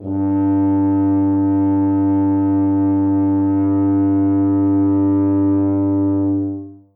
instruments / brass / tuba / samples / G2.mp3